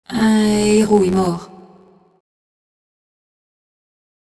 Vocodeur
son modifié : la première partie du son est prononcée plus lentement, et la deuxième est accélérée.